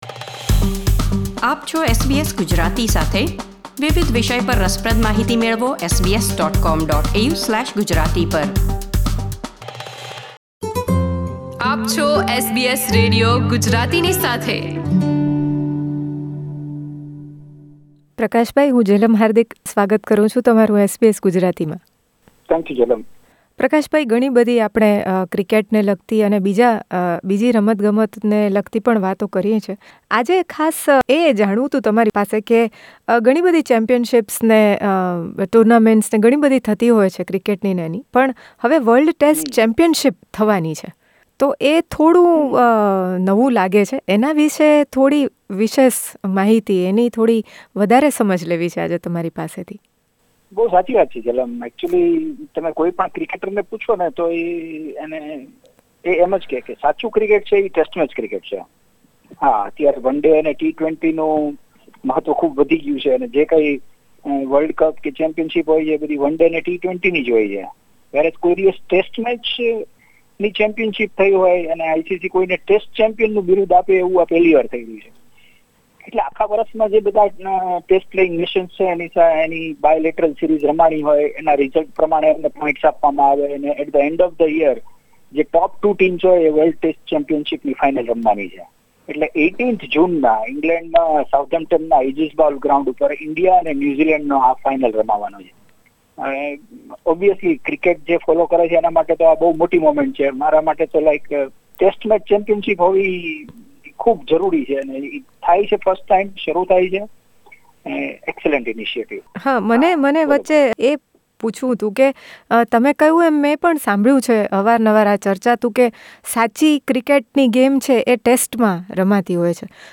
ક્રિકેટ નિષ્ણાત